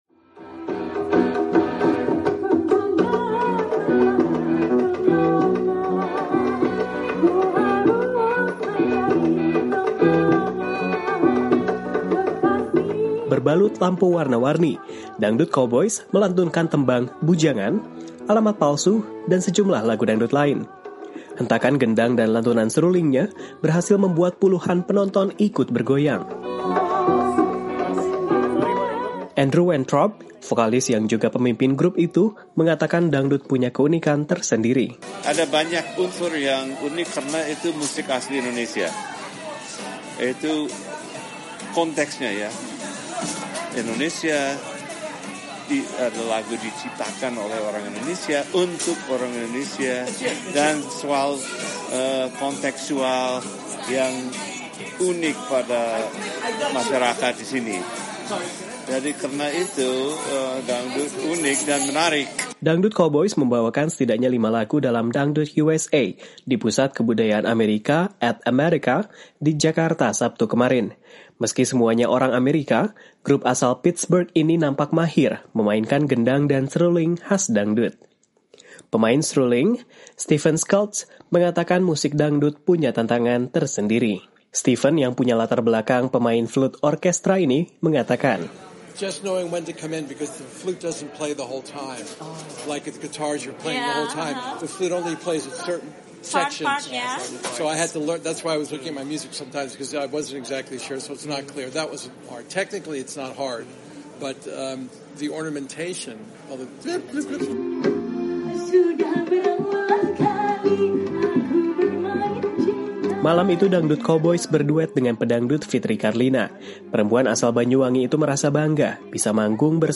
Hentakan gendang dan lantunan serulingnya berhasil membuat puluhan penonton ikut bergoyang.
Meski semuanya orang Amerika, grup asal Pittsburgh ini nampak mahir memainkan gendang dan seruling khas dangdut.